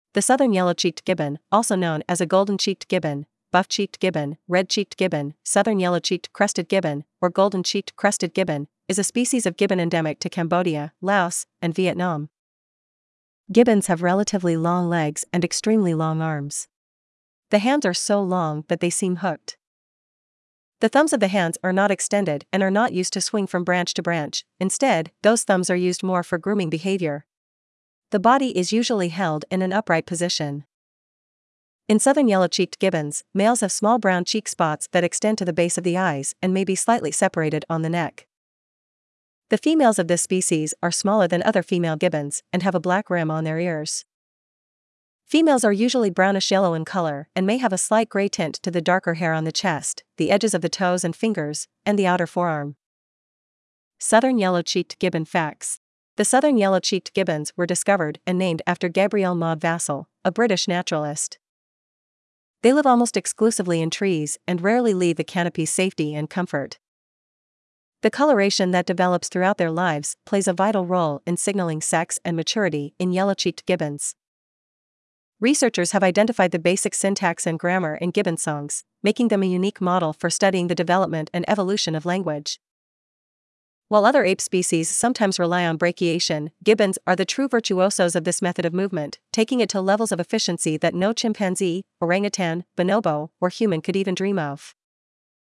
Southern Yellow-Cheeked Gibbon
southern-yellow-cheeked-gibbon.mp3